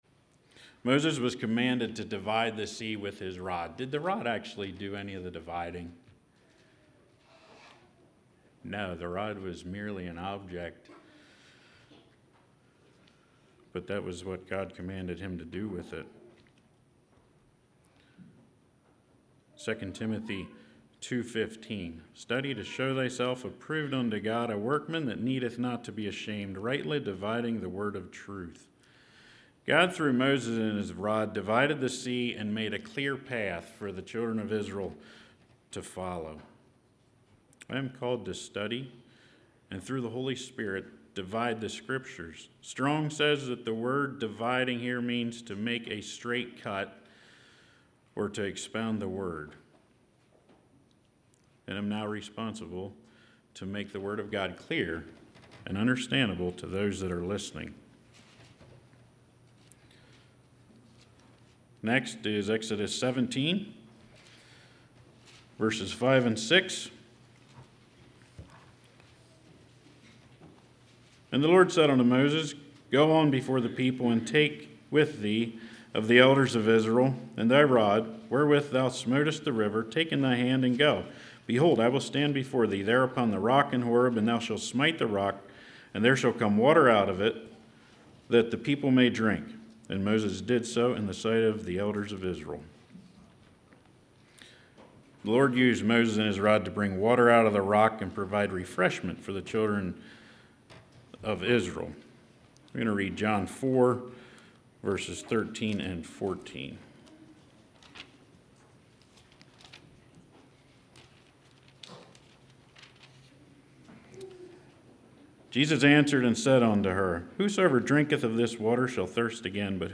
Congregation: Shirksville